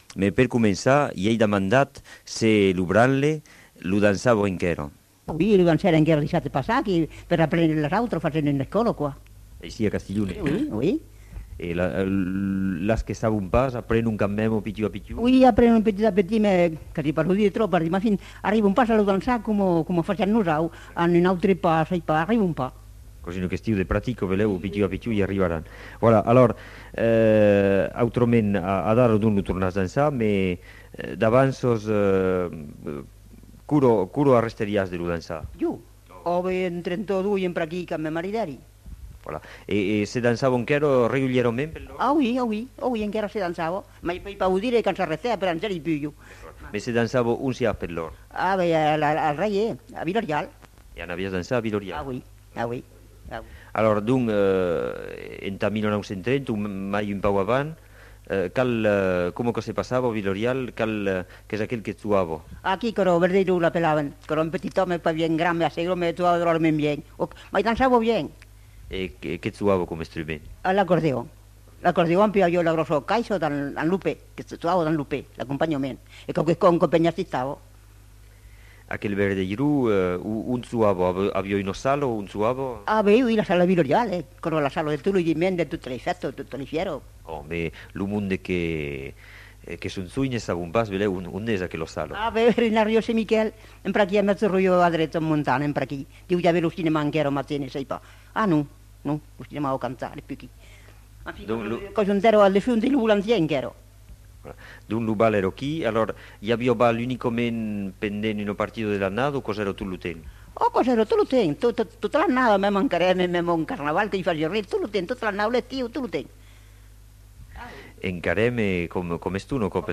Lieu : Villeréal
Genre : témoignage thématique